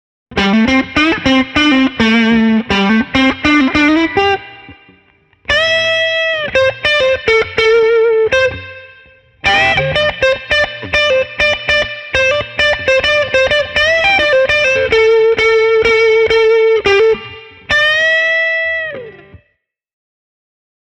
The Glendora NLT’s displays an open voice with a nicely clucking attack.
I have only good things to say about GJ2’s Habanero pickups – they sound great, they are dynamically rich and the whole set is well balanced in terms of tone and output level.
Here’s a short clip played using the neck pickup: